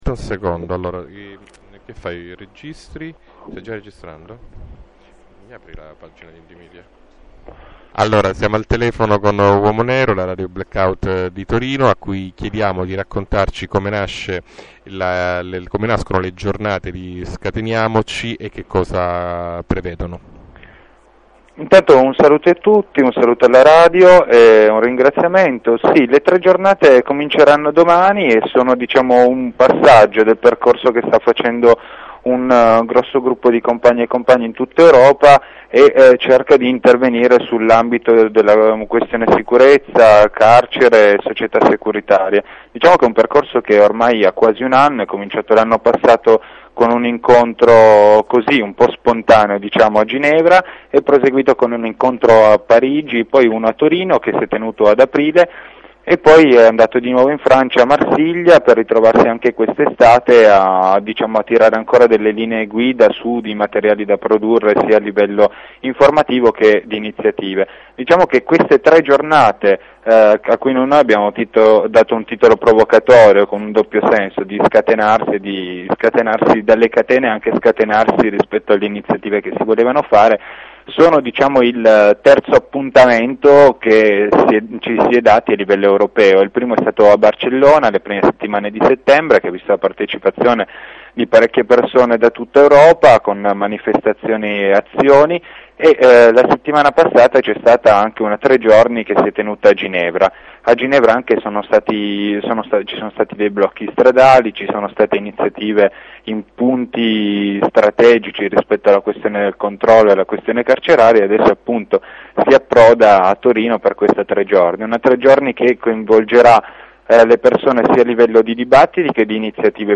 Intervista e presentazione di Scateniamoci : Italy imc